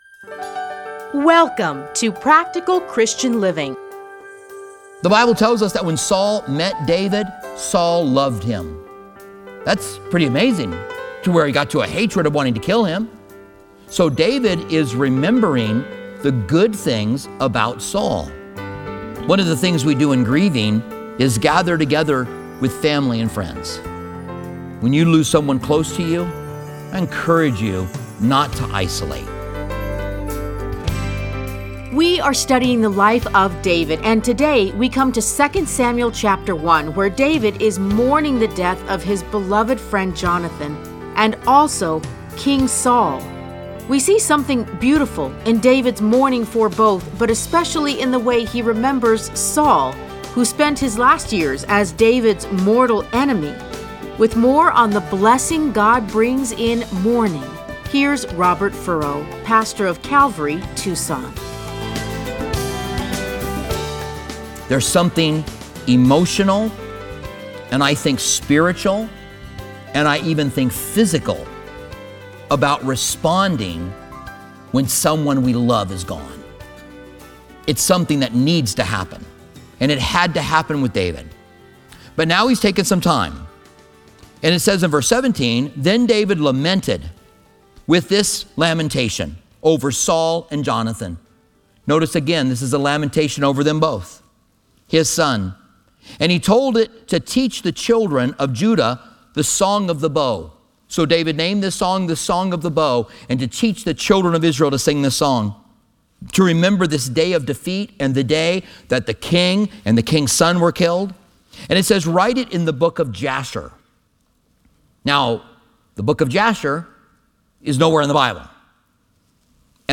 Listen to a teaching from 2 Samuel 1:1-27.